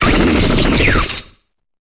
Cri de Lucanon dans Pokémon Épée et Bouclier.